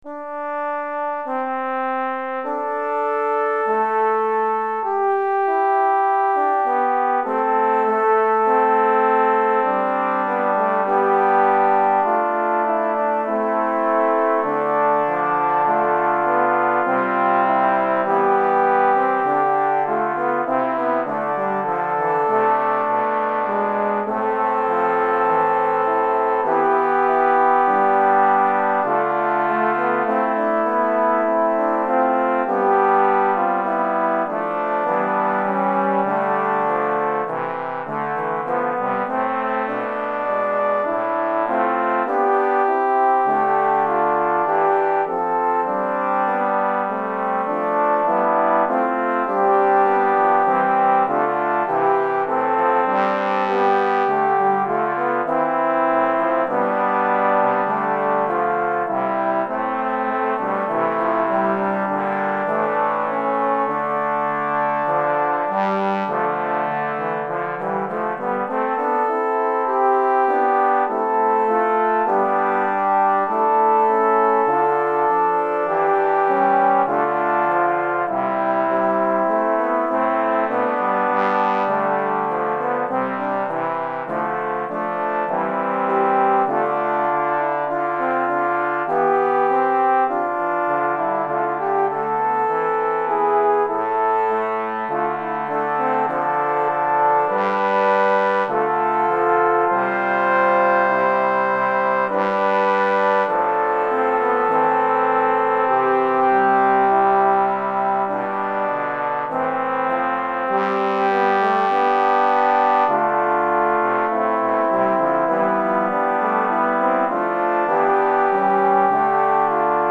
Répertoire pour Trombone - 4 Trombones